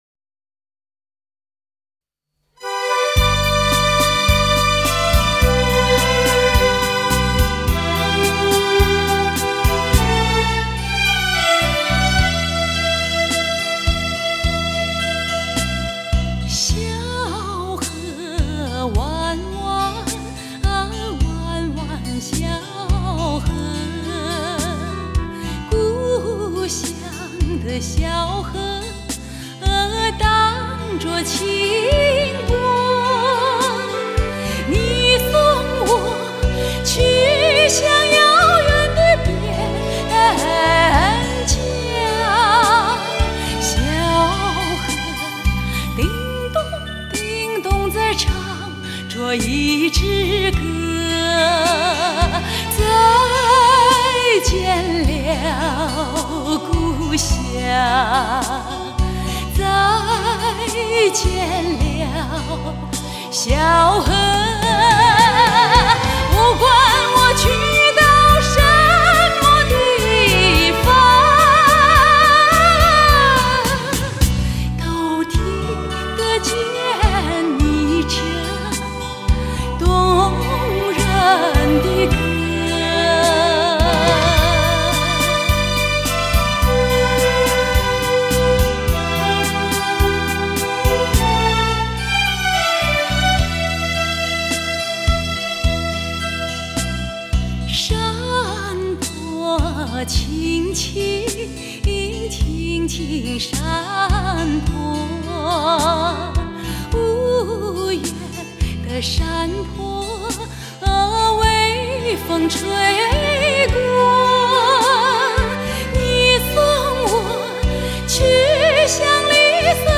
曲调旋律优美和歌词的意境很搭配。